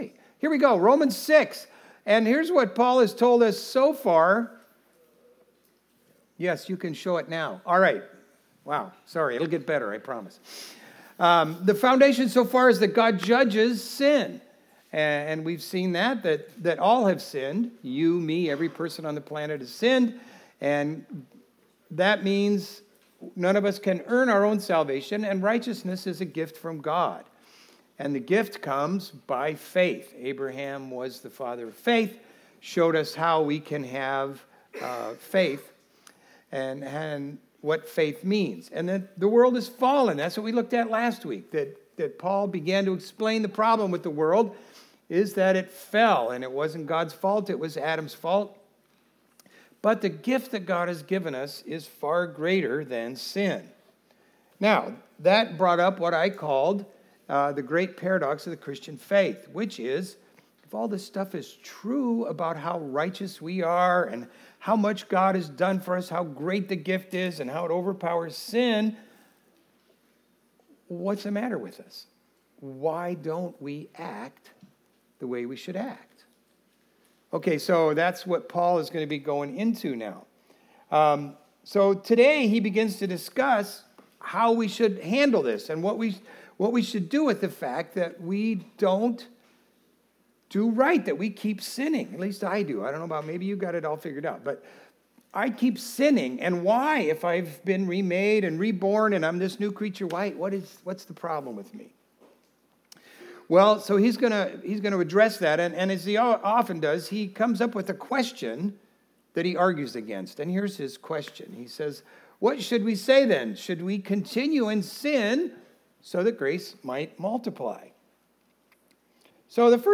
Video Audio Download Audio Home Resources Sermons A Matter of Life and Death Aug 03 A Matter of Life and Death Understanding the reality of our death is the doorway to the new life Jesus has given us.